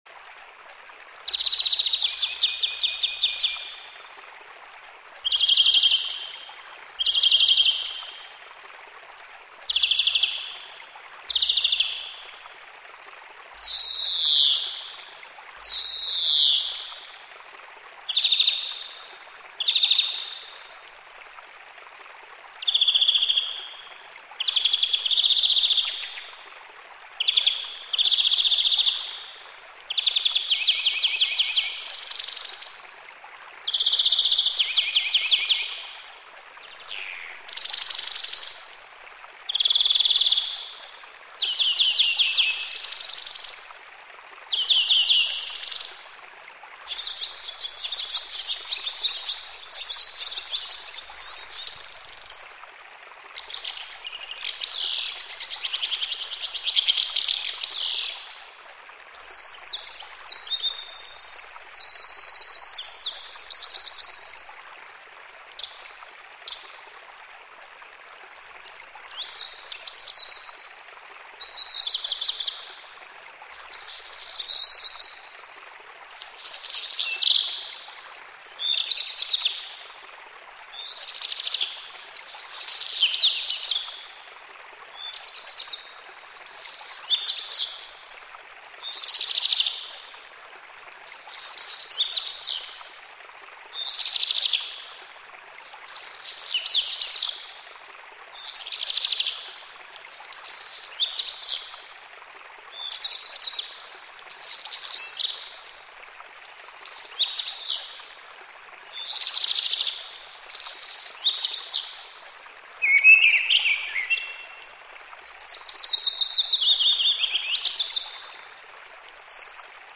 ptaki.mp3